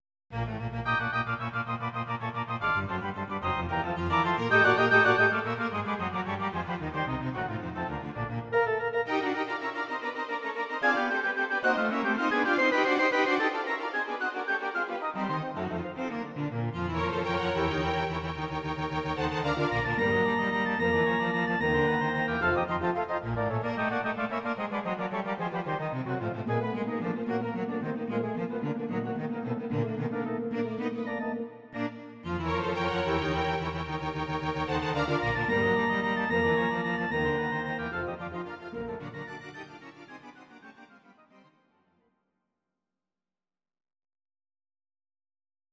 Audio Recordings based on Midi-files
Classical, Instrumental